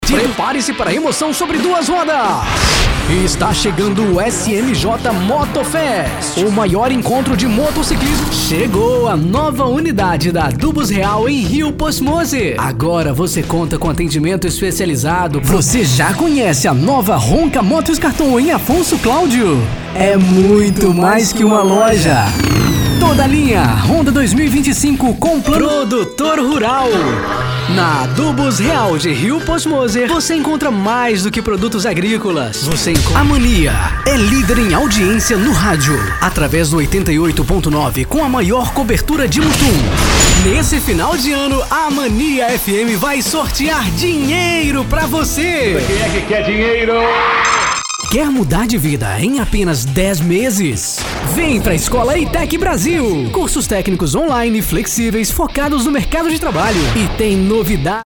Estilo(s):